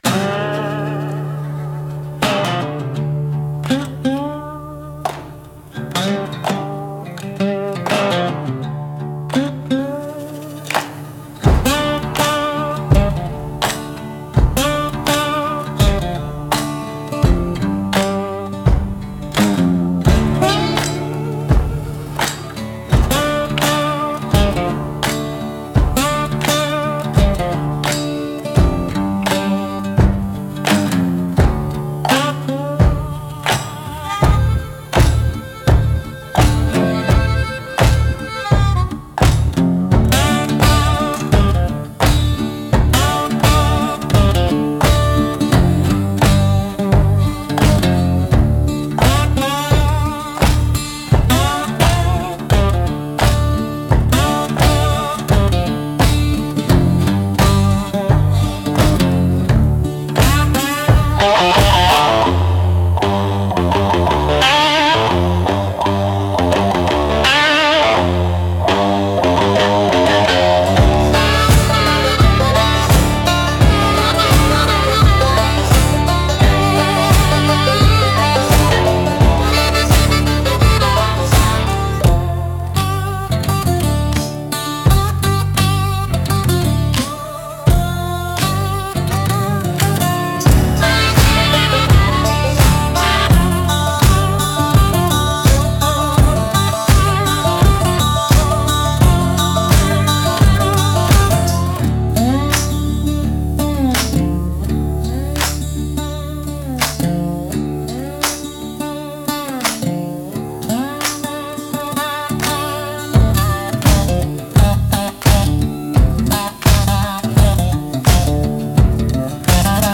Instrumental - Stomp on the Company Line 3.07